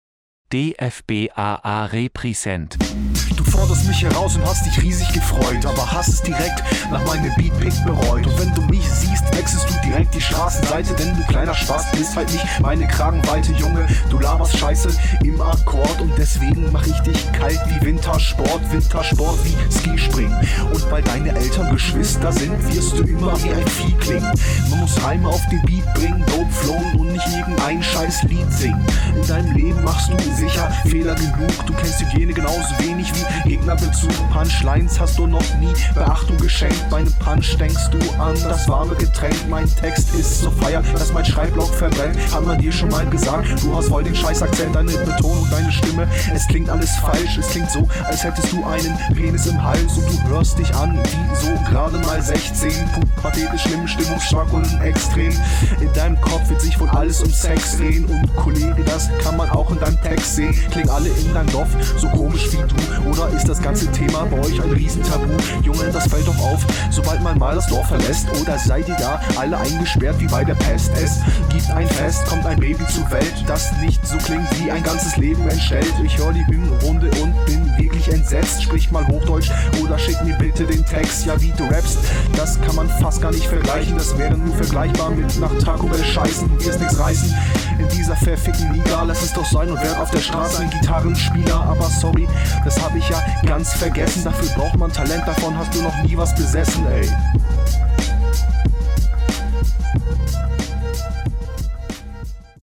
Deine stimme versinkt gerade im Mix, einfach -5db beim Beat machen und das wirkt wunder.